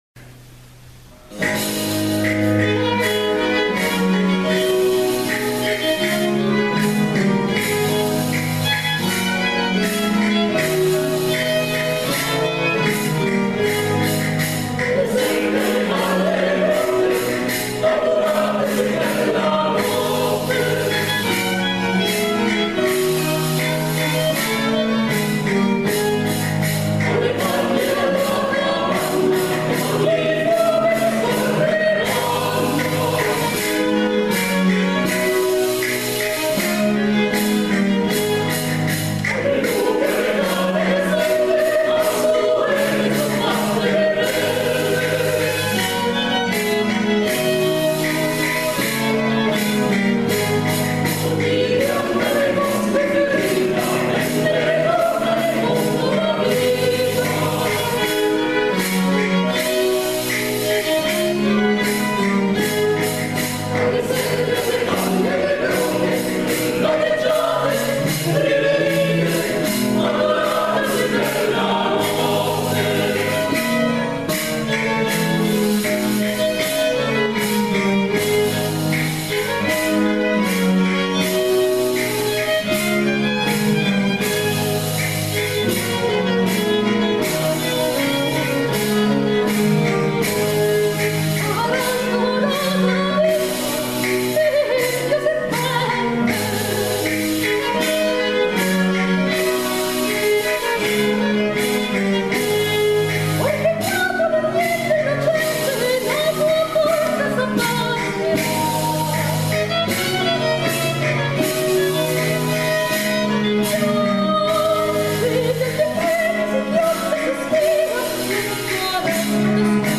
ENSEMBLE BAROCCA SABINO -
5-Tarantella.mp3